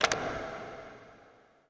ARROWBTN.WAV